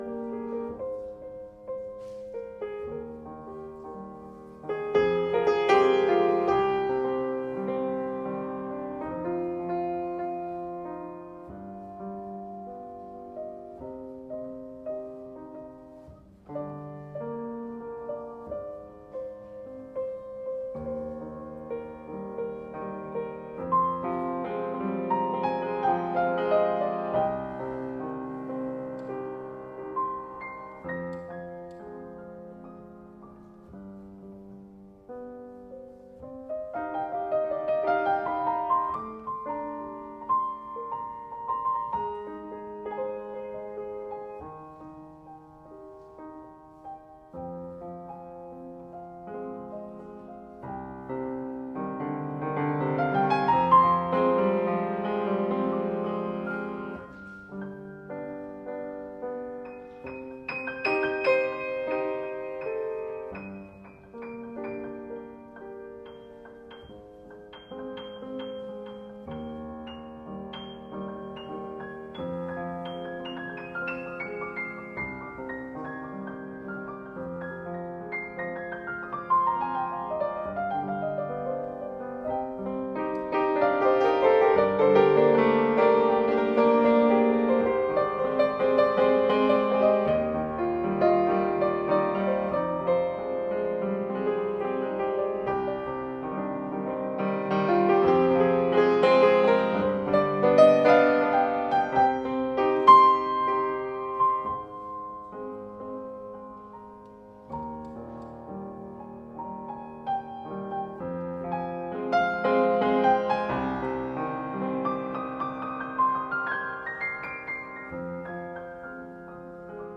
in recital Dec 2016